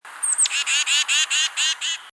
tufted titmouse
"Tee-dee-dee" variant from Tufted Titmouse, November 1999, Oxford, Ohio